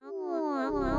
Catsoundsourcerealnotfake Bouton sonore